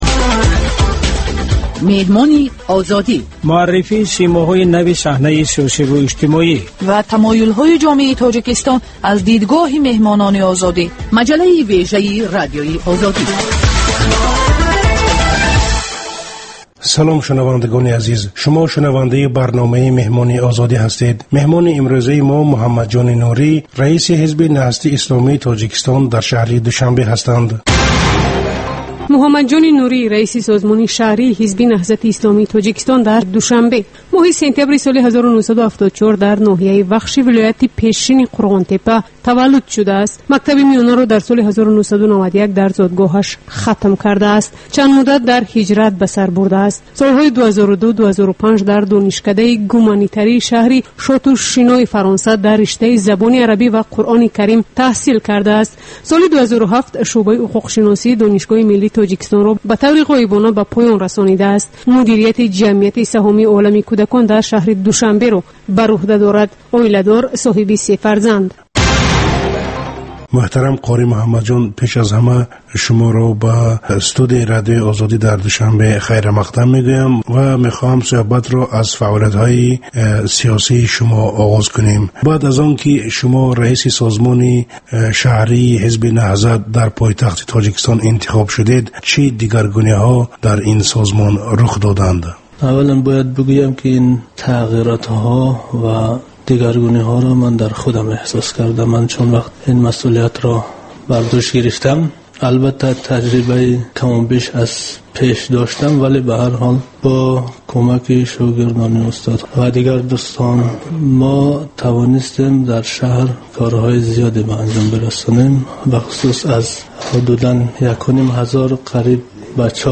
Гуфтугӯи ошкоро бо чеҳраҳои саршинос, намояндагони риштаҳои гуногун бо пурсишҳои ғайриодӣ.